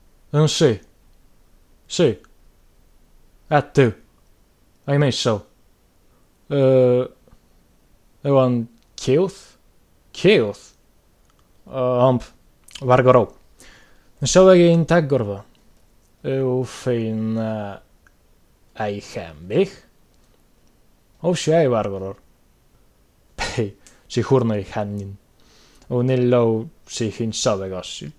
Dialog_salladorski_przykład.mp3